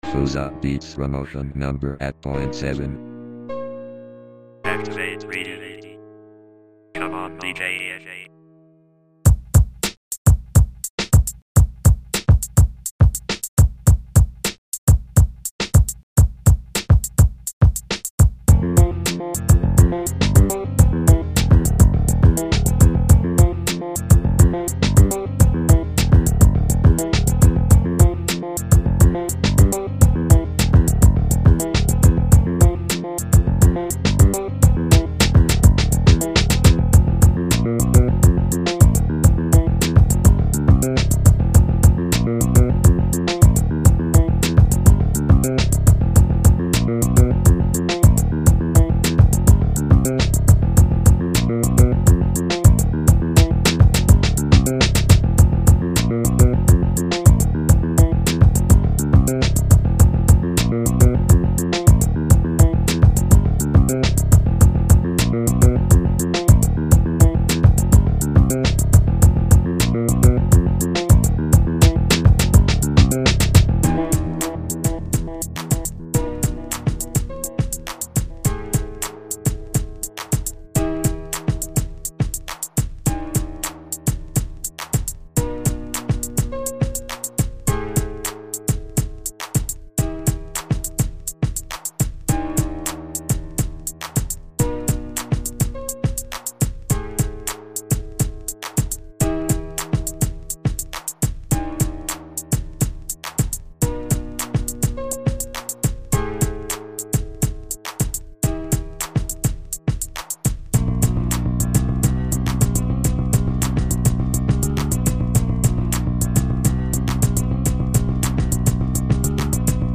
inst hiphop
ベースラインとピアノをダブらして音数を増やしてみたけど以外とサマになったのでは。